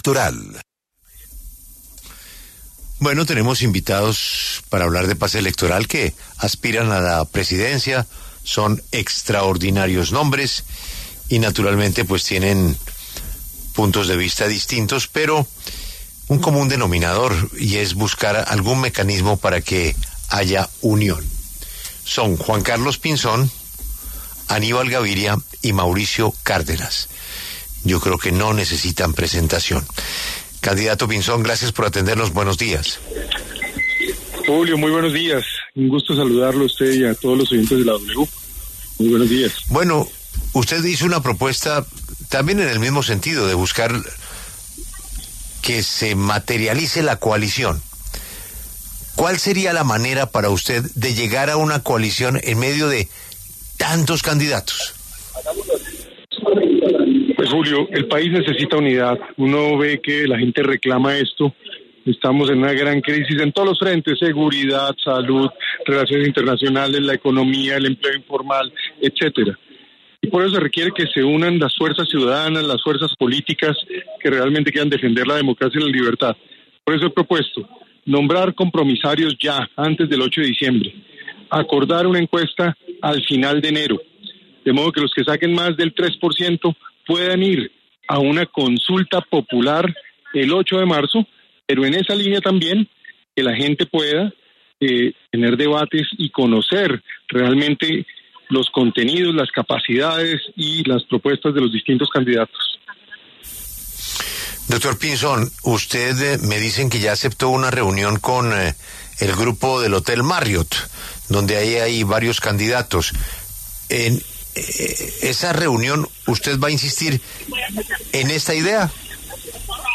Los precandidatos Juan Carlos Pinzón, Aníbal Gaviria y Mauricio Cárdenas pasaron por los micrófonos de La W.